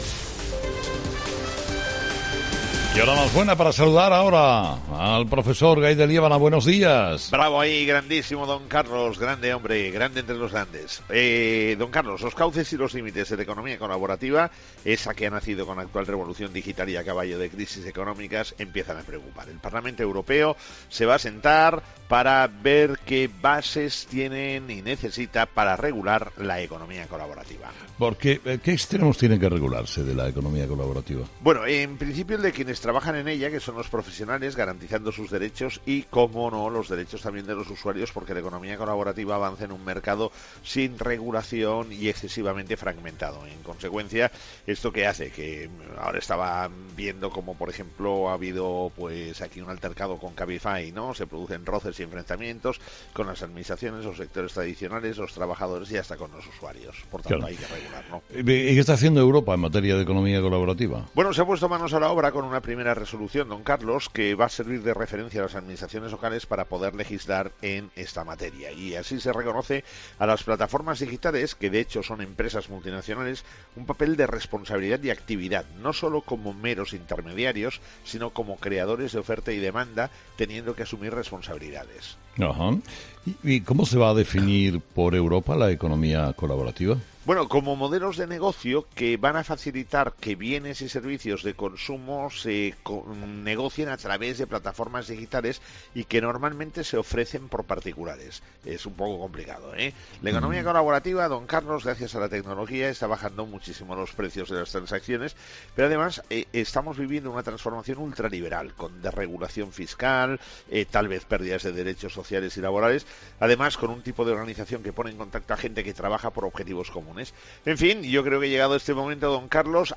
AUDIO: La primera reflexión económica del día en 'Herrera en COPE' con el profesor Gay de Liébana